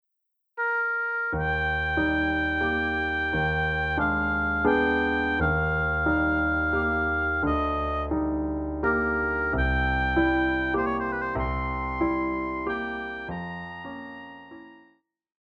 古典
雙簧管
鋼琴
獨奏與伴奏
有節拍器